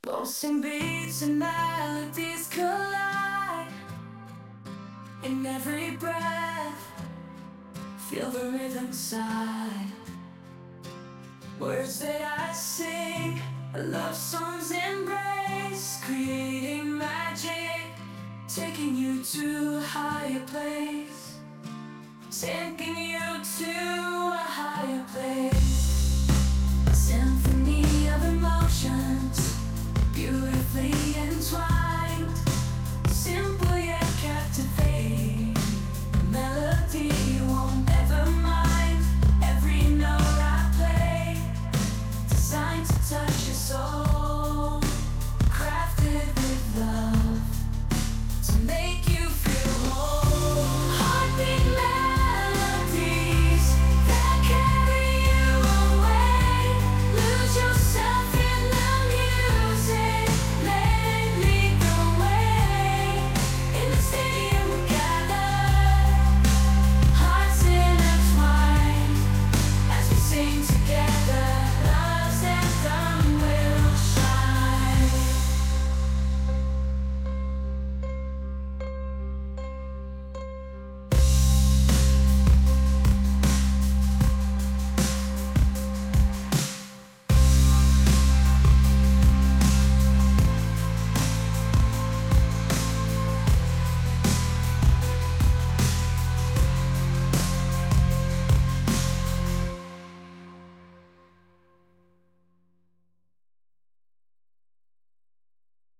Electric Bass, Drums, Electric Guitars, Chorus
Genre: Blues and Rock